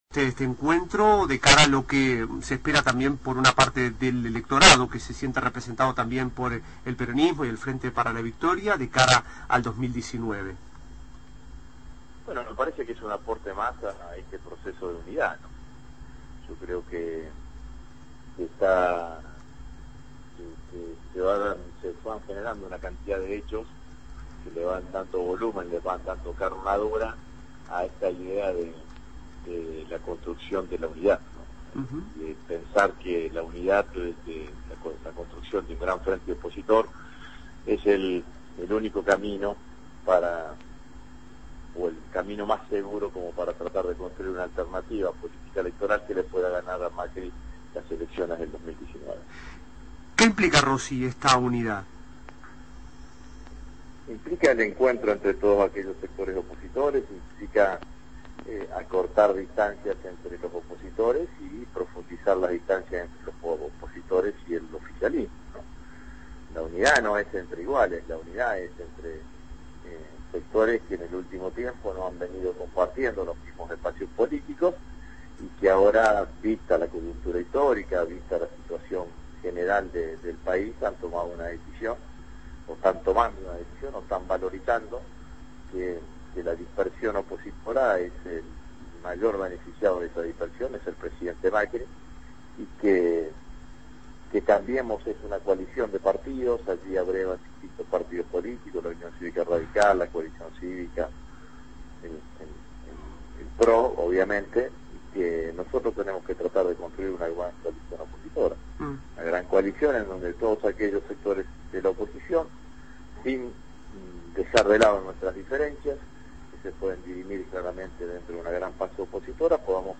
Entrevistado: Agustín Rossi (Diputado nacional)